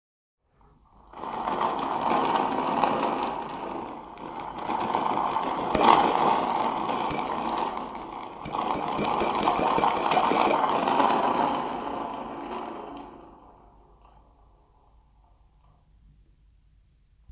牛柄のカンでできたスペーシーなスプリングパーカッション